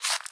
Sfx_creature_penguin_foot_slow_walk_06.ogg